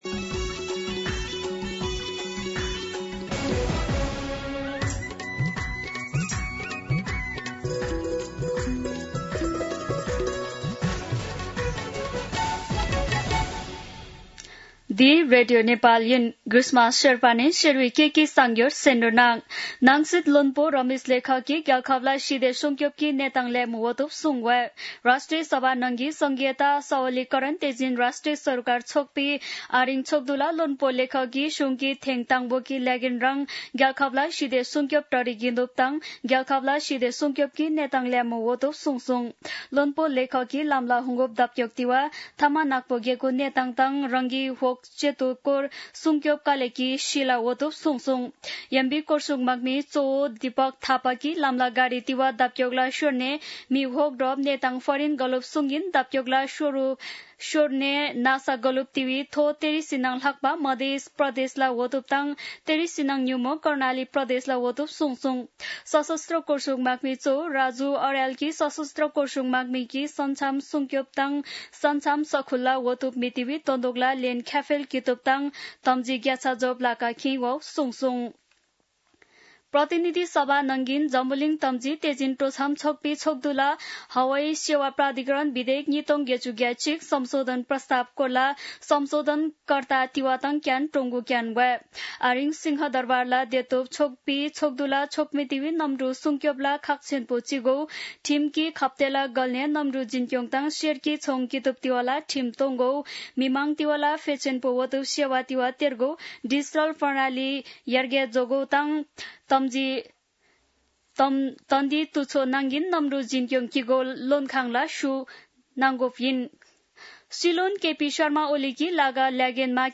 शेर्पा भाषाको समाचार : २ साउन , २०८२